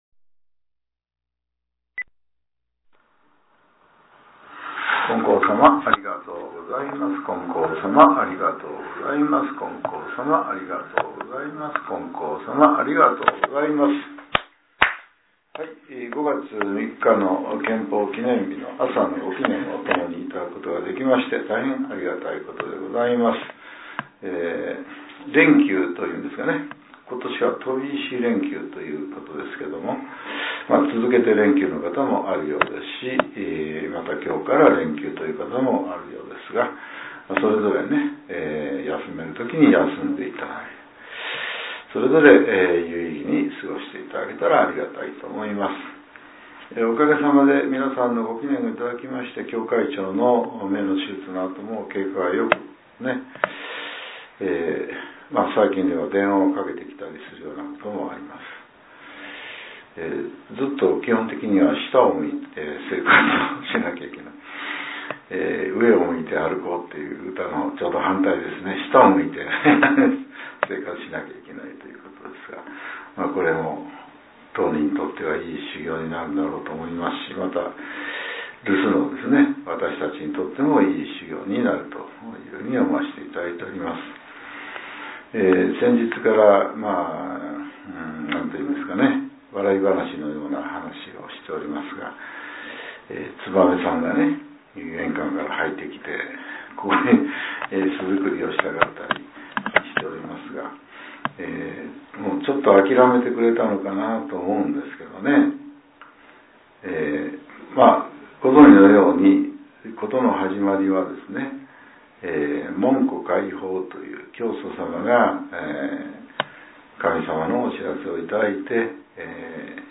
令和７年５月３日（朝）のお話が、音声ブログとして更新されています。